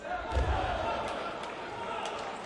描述：体育摔跤欢呼掌声体育场
Tag: 体育场 掌声 摔跤 体育 欢呼声